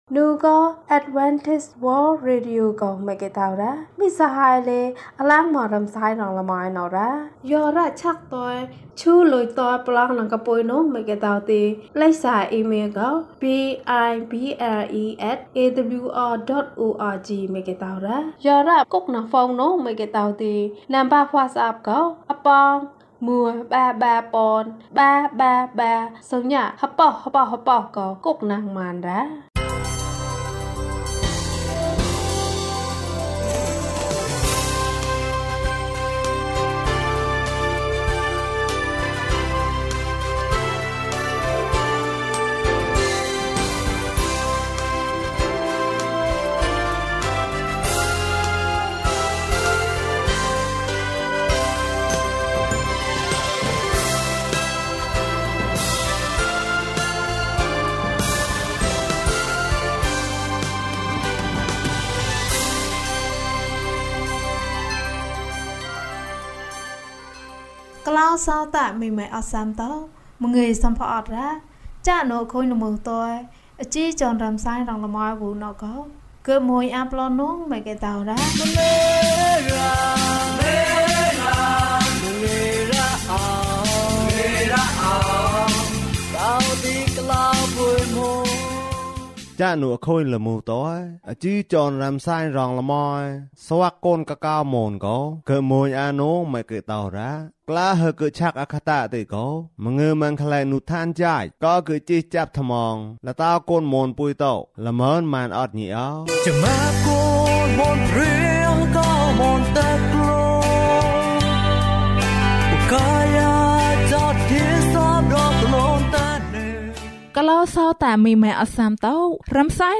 ဘုရားသခင်သည် ချစ်ခြင်းမေတ္တာဖြစ်သည်။၀၂ ကျန်းမာခြင်းအကြောင်းအရာ။ ဓမ္မသီချင်း။ တရားဒေသနာ။